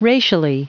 Prononciation du mot racially en anglais (fichier audio)
Prononciation du mot : racially